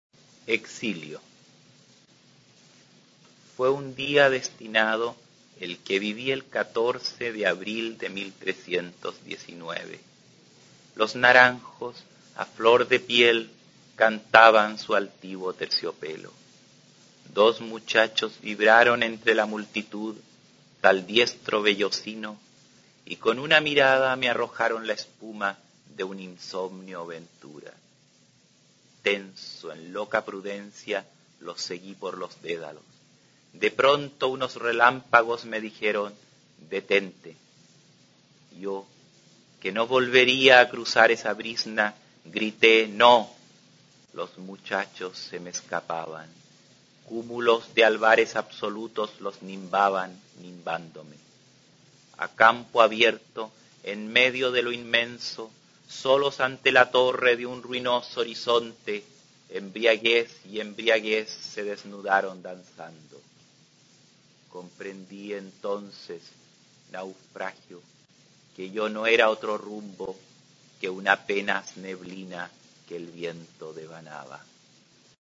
Aquí se puede oír al escritor chileno David Rosenmann-Taub leyendo su poema Exilio.